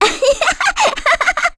Lilia-Vox_Happy2.wav